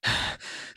breath1.ogg